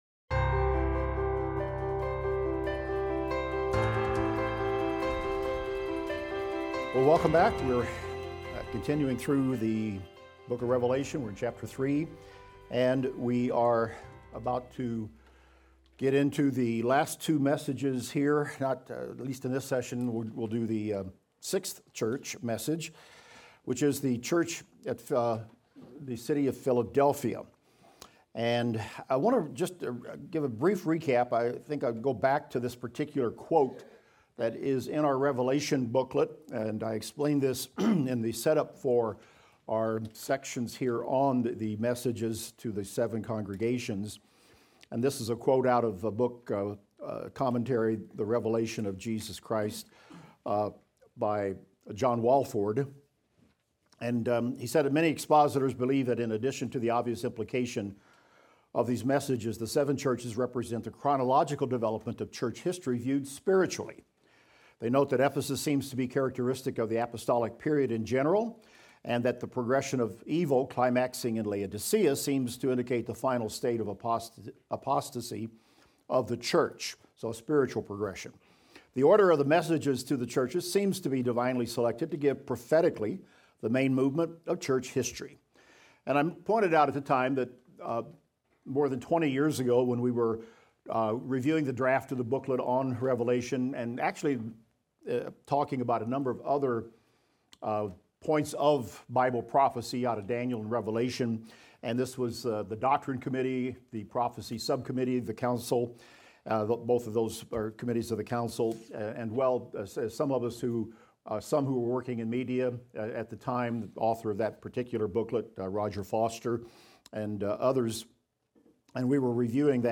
Revelation - Lecture 33 - audio.mp3